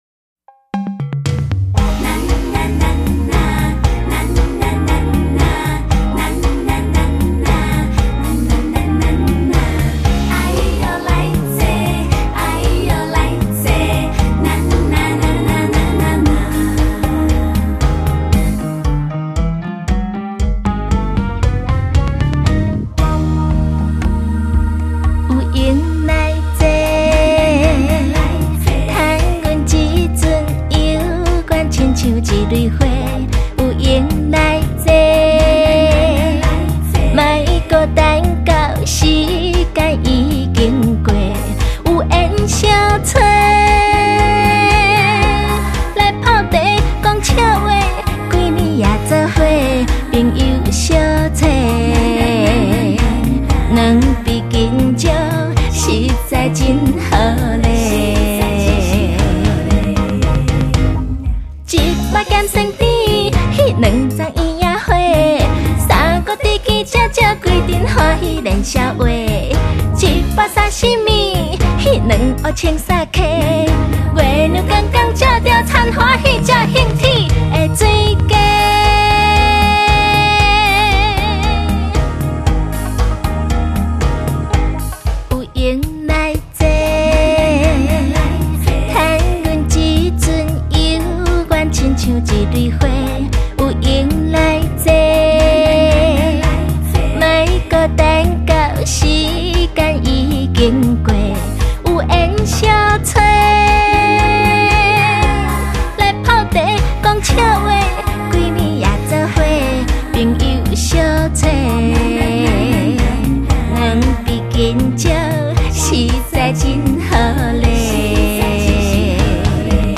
情歌香醇絕美．恰恰嬌媚迷人
恰恰風韻熱曲?KTV開嗓熱場歡唱首選！